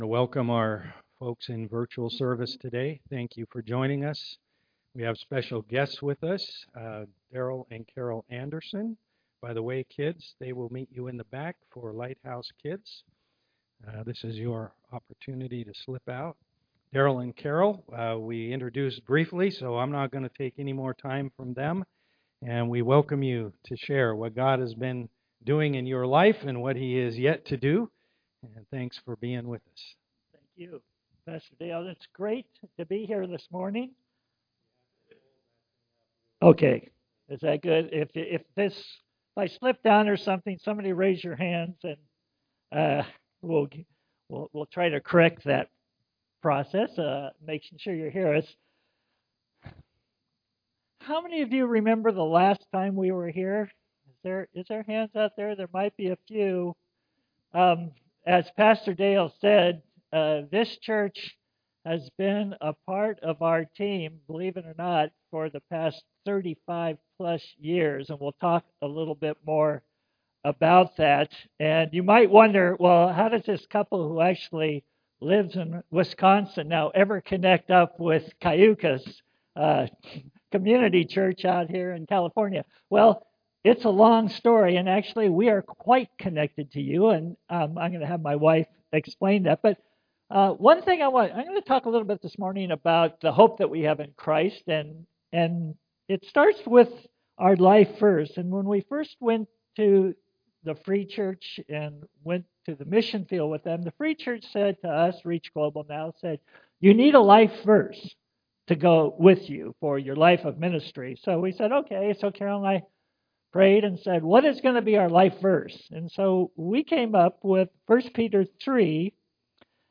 Missionary Speakers Passage: 1 Peter 3:15 Service Type: am worship Gratitude and Hope.